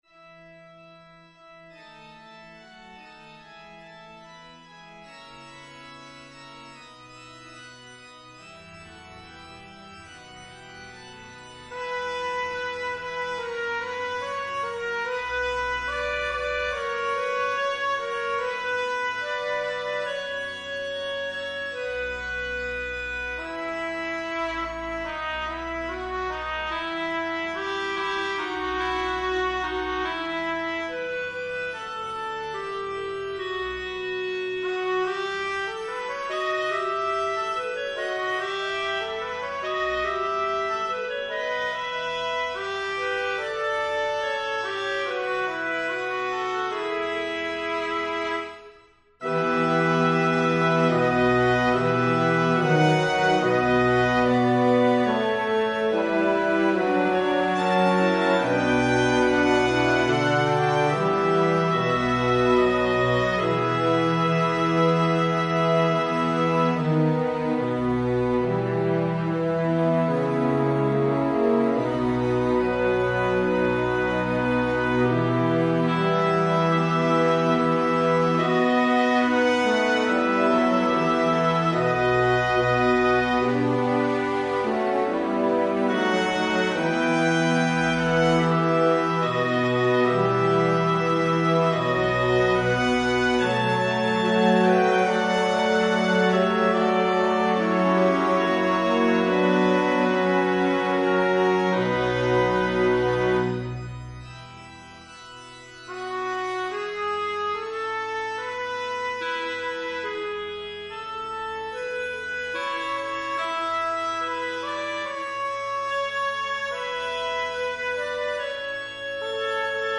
Evensong Setting